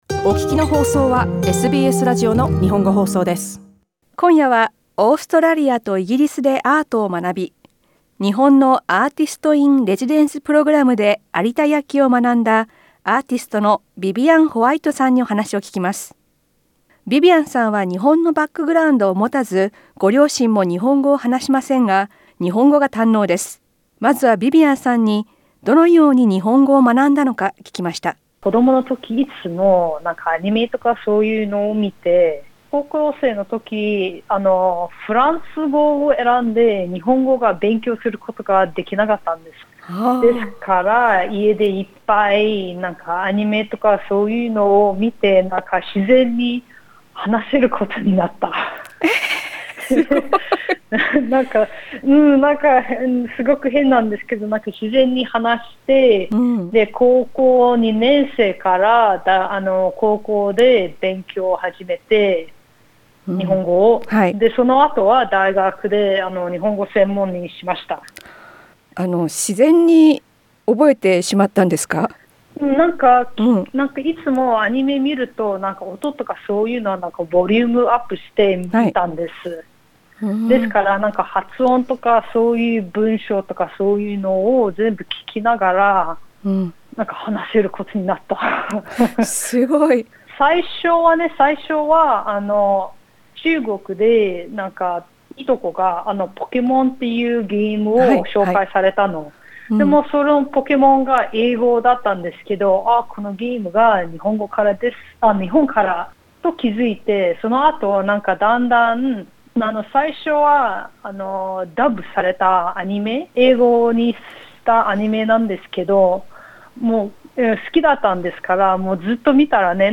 インタビューでは、日本語の勉強方法や、日本での体験や抱いた感想などを聞きました。